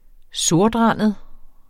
Udtale [ -ˌʁɑnˀəð ]